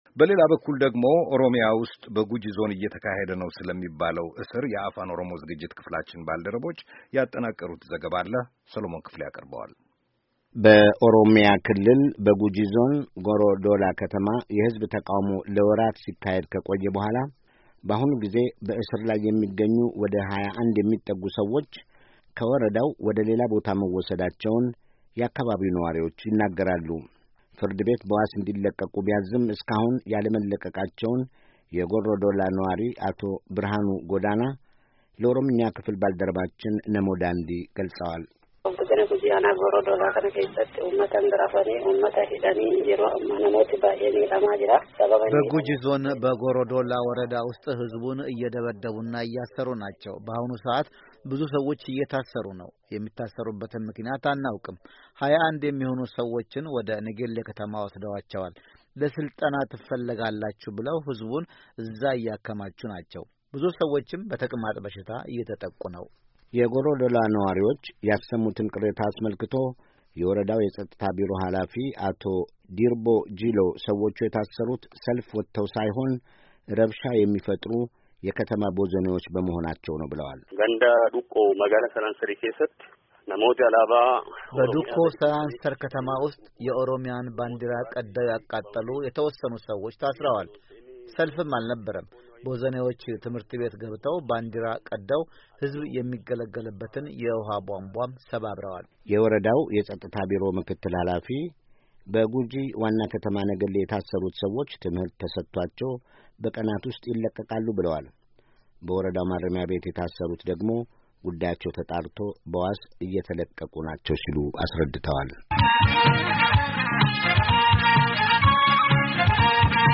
ኦሮምያ ውስጥ በጉጂ ዞን እሥራት እየተካሄደ መሆኑ ተገልጿል፡፡ የአፋን ኦሮሞ ዝግጅት ክፍላችን ባልደረቦች ያጠናቀሩትን ዘገባ አለ ከተያያዘው የድምፅ ፋይል ያዳምጡ፡፡